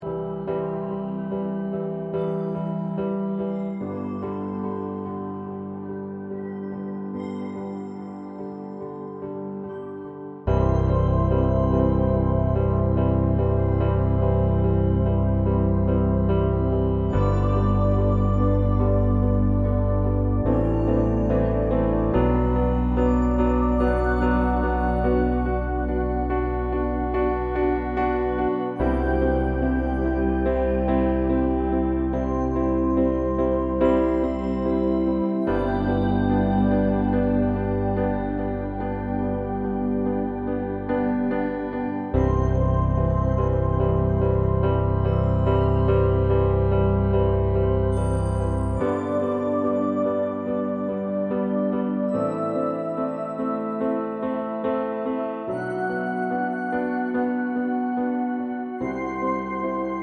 no Backing Vocals Rock 4:17 Buy £1.50